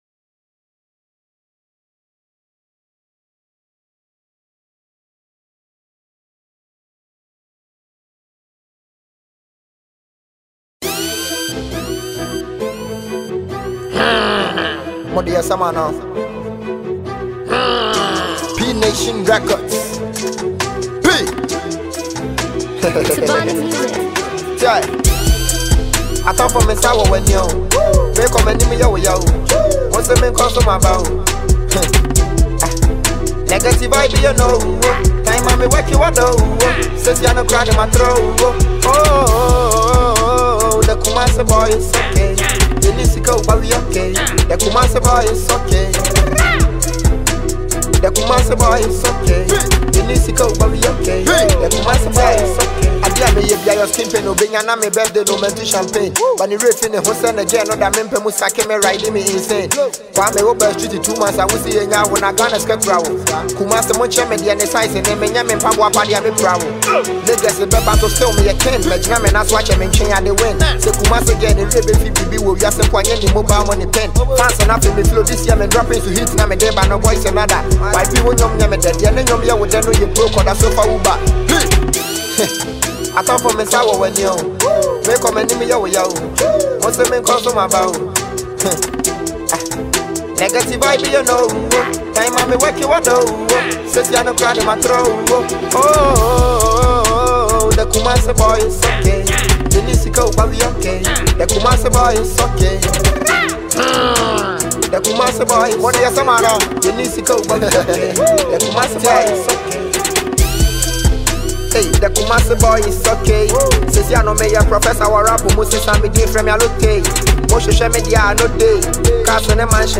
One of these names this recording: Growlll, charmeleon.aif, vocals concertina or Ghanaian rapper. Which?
Ghanaian rapper